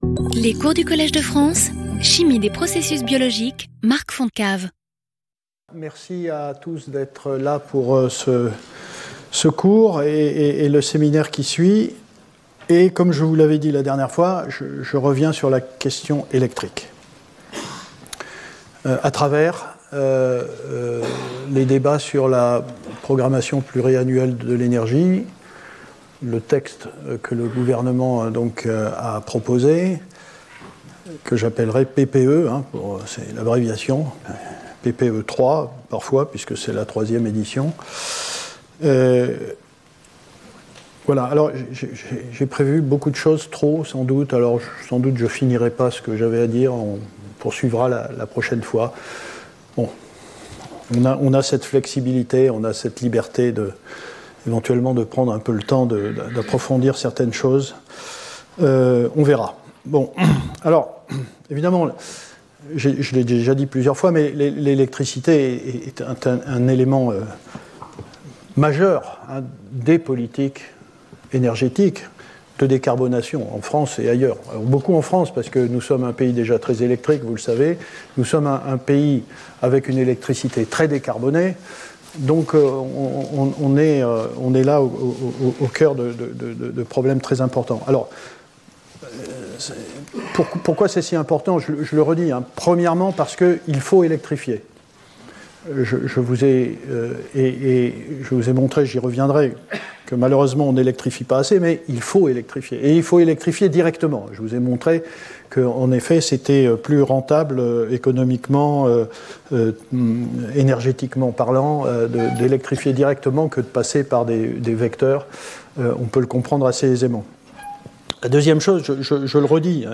Cours